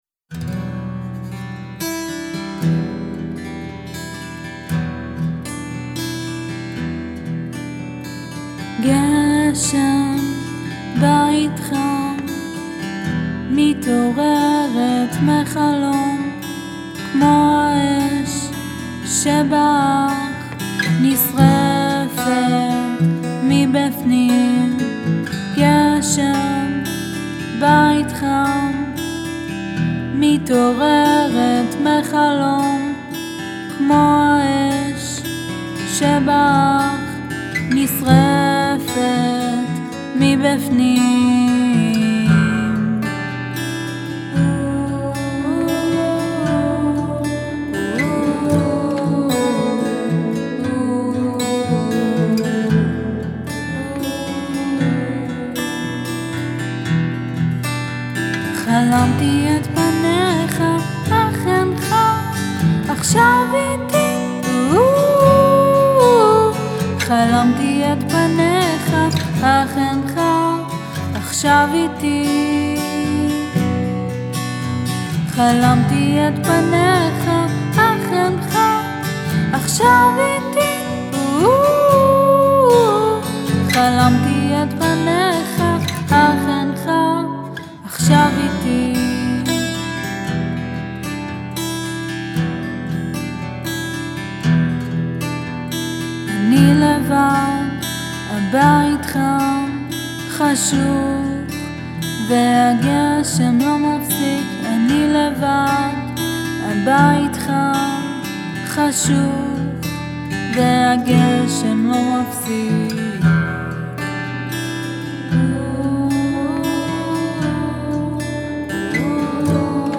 גשם (שירת נשים)